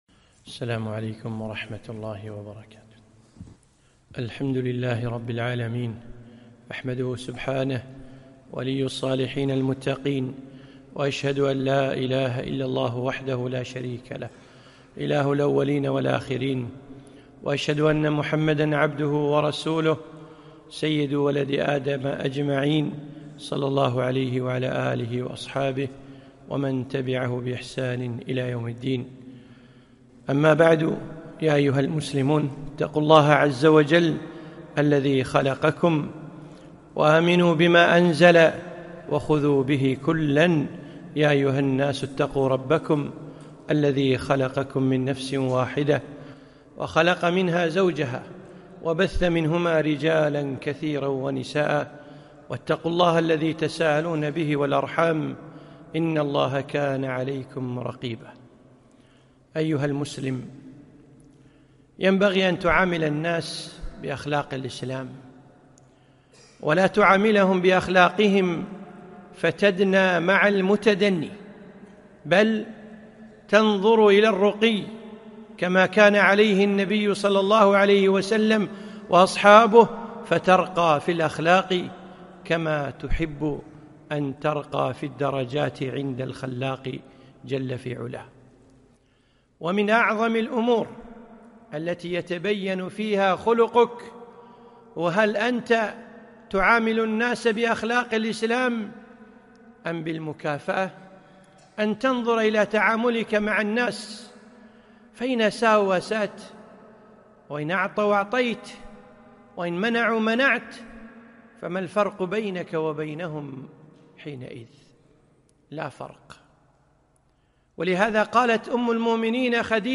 خطبة - كن واصلا